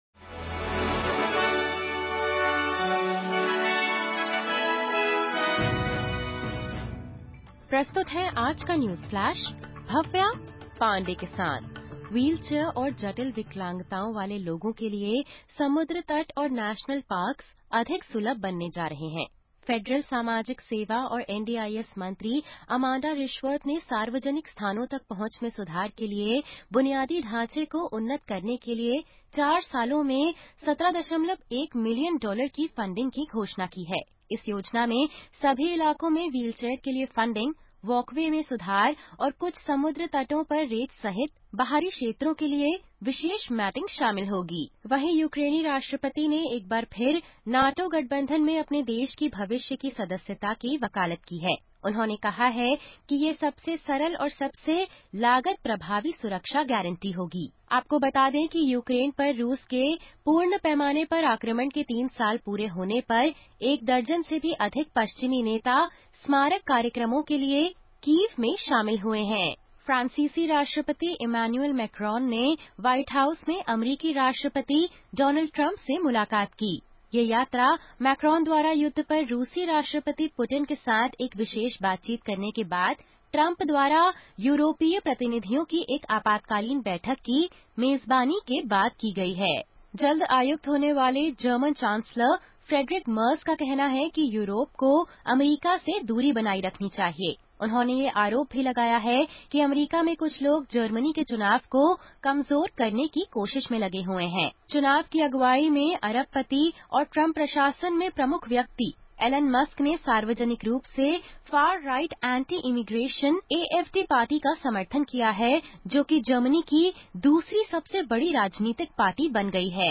सुनें ऑस्ट्रेलिया और भारत से 25/02/2025 की प्रमुख खबरें।